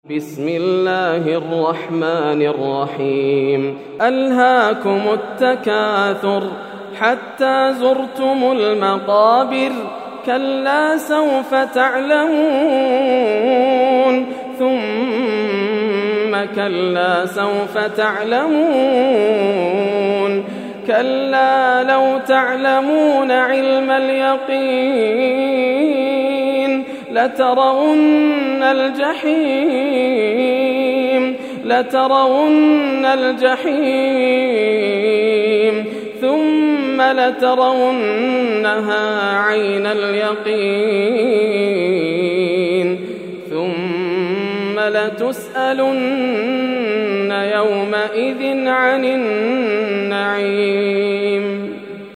سورة التكاثر > السور المكتملة > رمضان 1431هـ > التراويح - تلاوات ياسر الدوسري